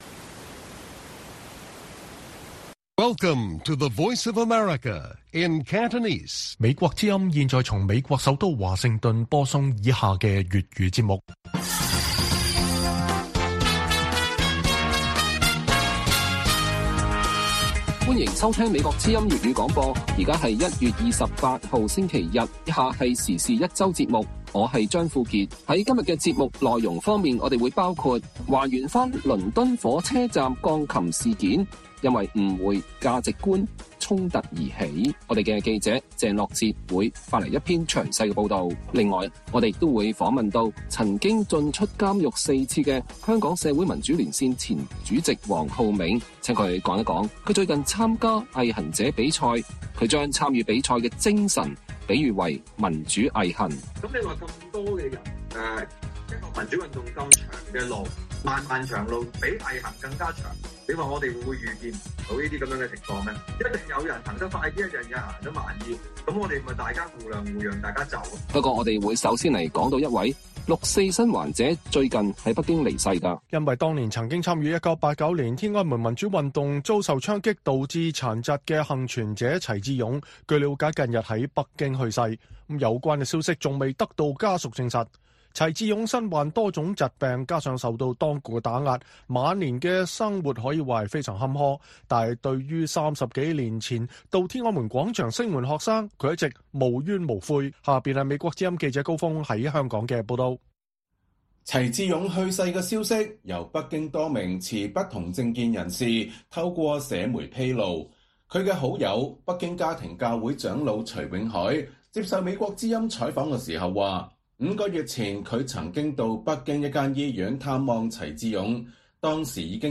粵語新聞 晚上9-10點 ： 台灣黑熊學院辦「藍大噪行動」戶外演訓，考驗台灣人戰災下的生存力